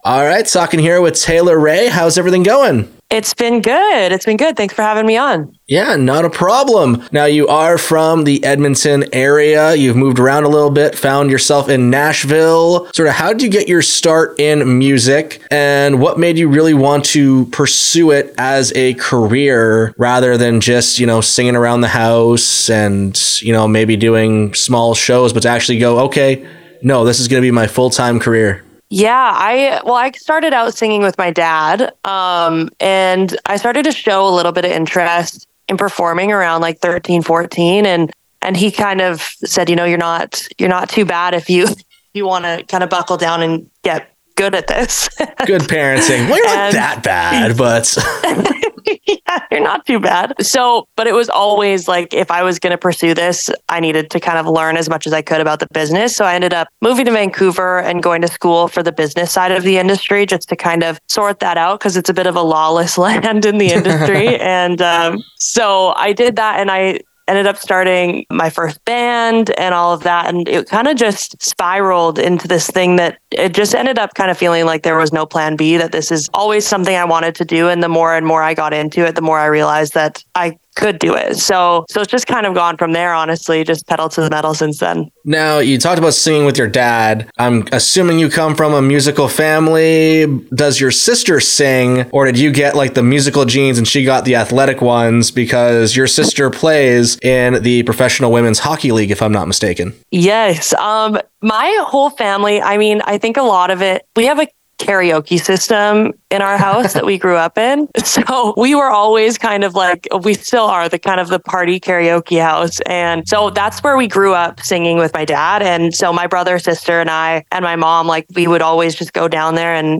Rising Stars Headliner Interview